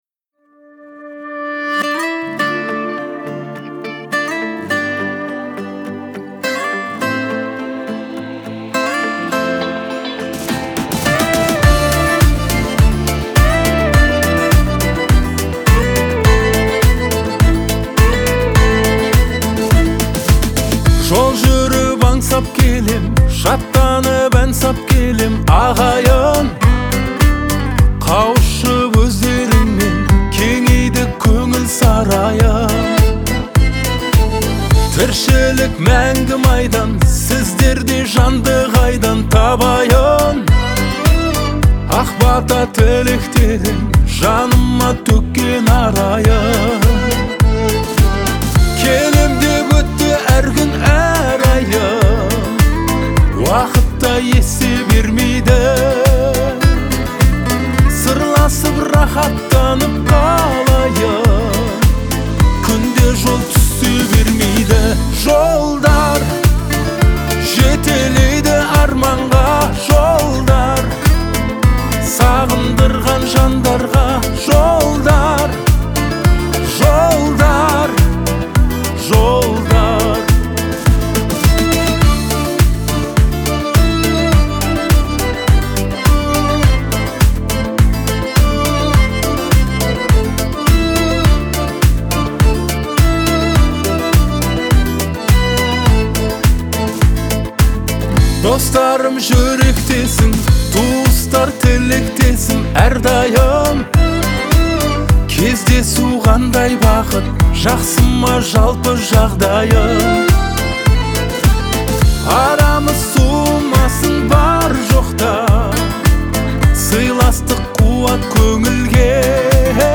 это проникновенное произведение в жанре казахского поп-фолка